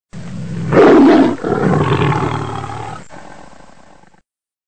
Play, download and share Tiger Roar original sound button!!!!
tiger-roar-sound-effect-no-copyright.mp3